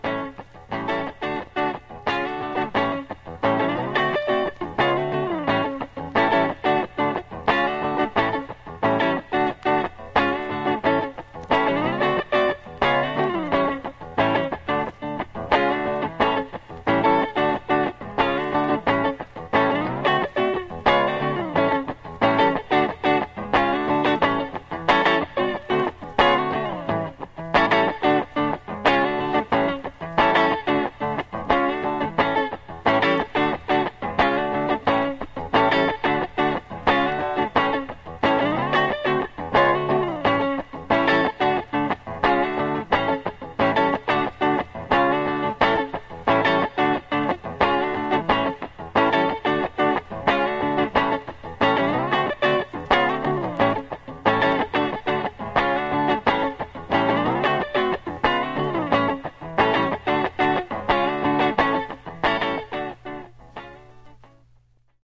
Avec par exemple les accords A (La) et D (Ré)
playback guitare de la méthode.